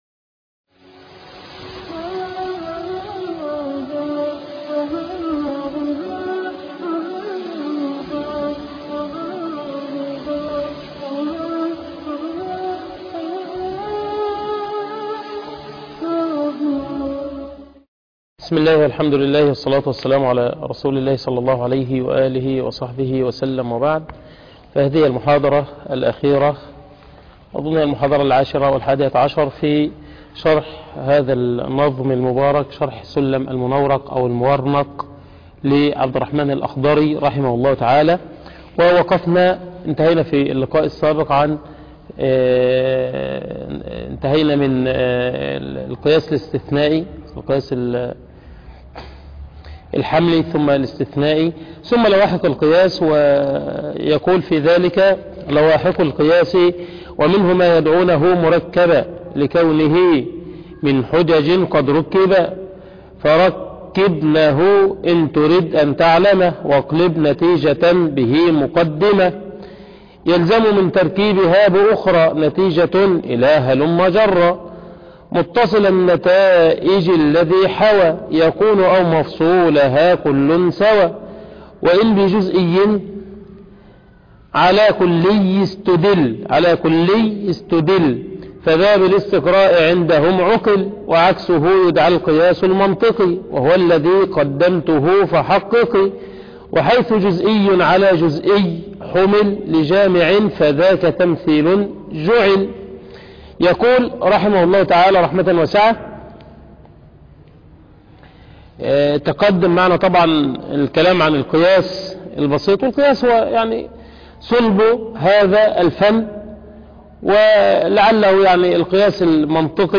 شرح متن السلم المنورق فى علم المنطق - المحاضرة التاسعة (الأخيرة)